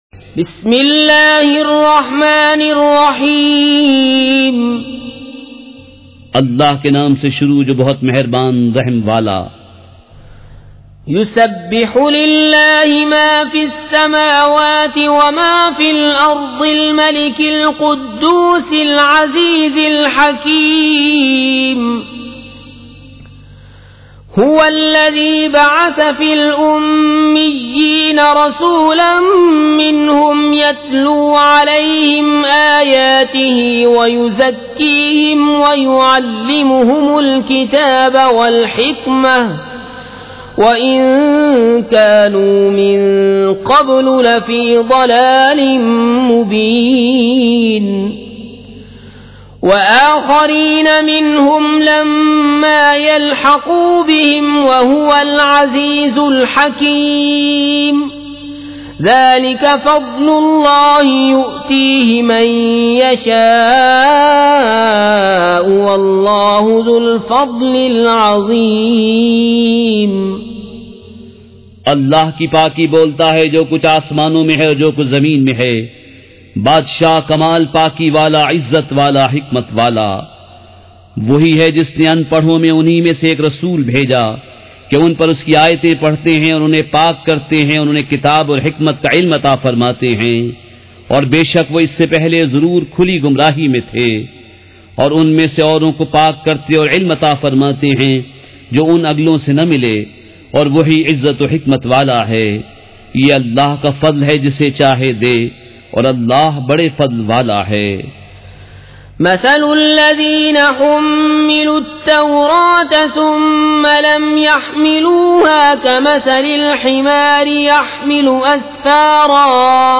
سورۃ الجمعۃ مع ترجمہ کنزالایمان ZiaeTaiba Audio میڈیا کی معلومات نام سورۃ الجمعۃ مع ترجمہ کنزالایمان موضوع تلاوت آواز دیگر زبان عربی کل نتائج 1691 قسم آڈیو ڈاؤن لوڈ MP 3 ڈاؤن لوڈ MP 4 متعلقہ تجویزوآراء